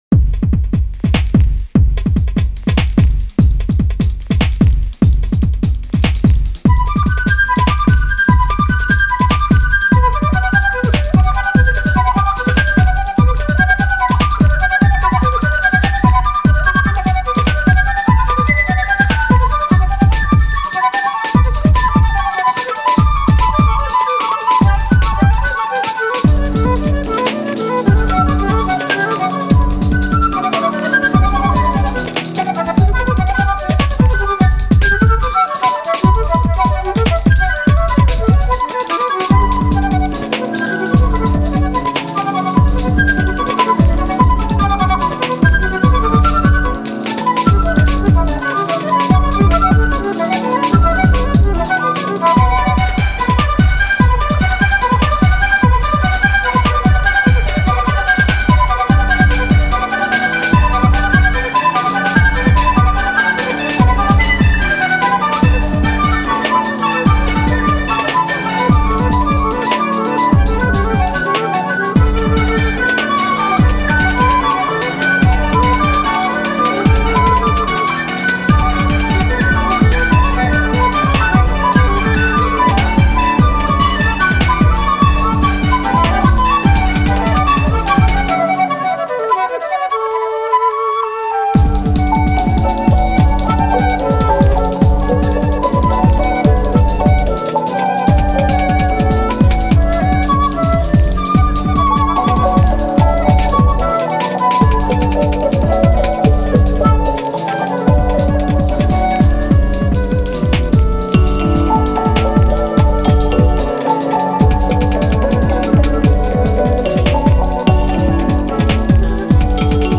World-renowned woodwind quintet